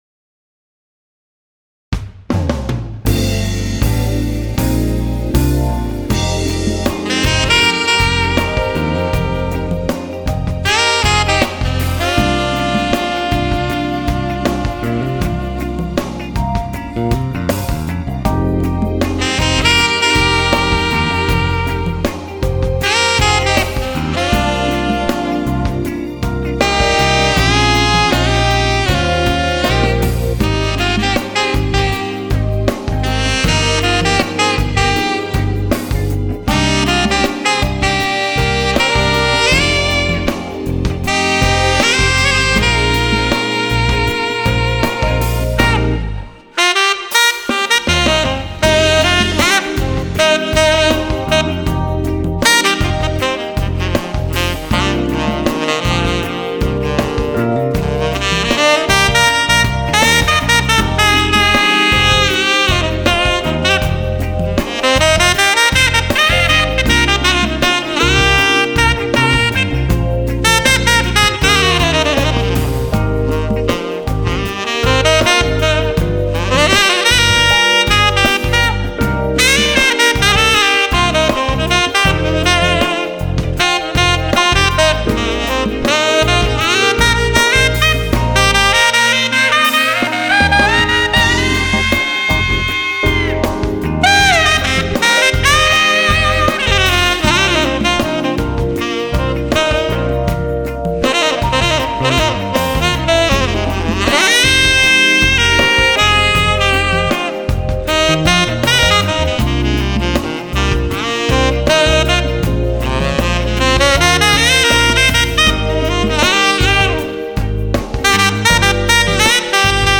Features and Tone: Moderately dark, thick and contoured